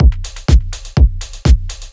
• cognitive drums house full - B.wav